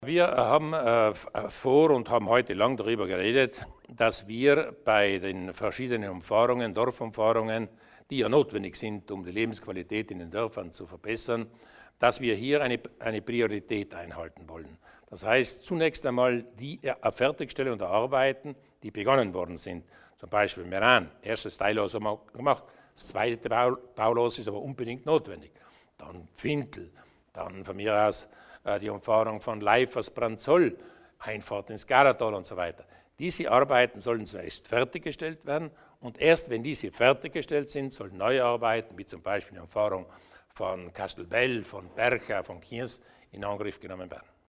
Landehauptmann Durnwalder über zukünftige öffentliche Arbeiten